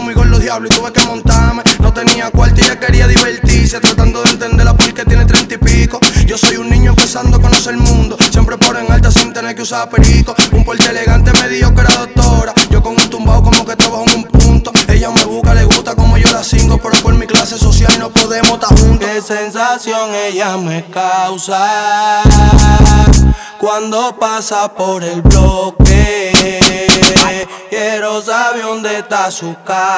Жанр: Латино
Urbano latino